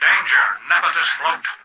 Excerpt of the reversed speech found in the Halo 3 Terminals.